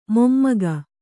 ♪ mommaga